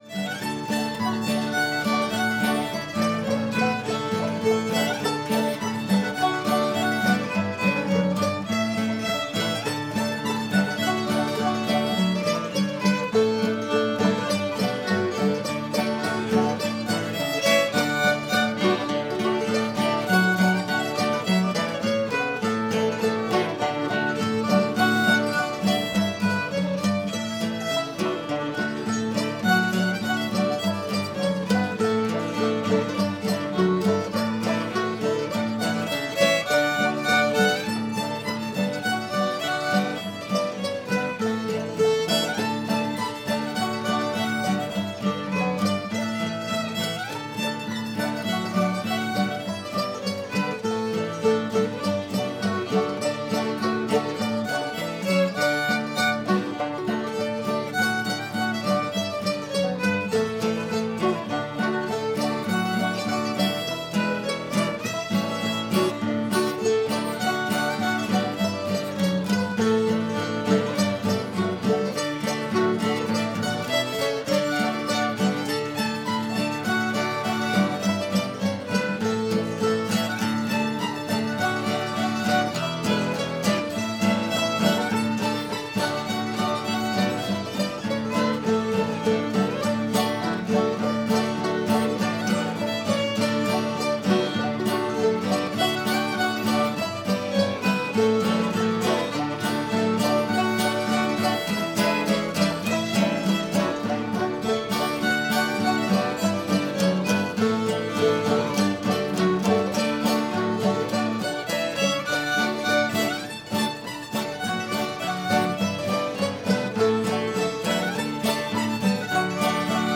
banjo tramp [D]